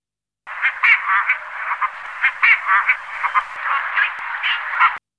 Cormoran pygmée
Phalacrocorax pygmeus
cormoran_p.mp3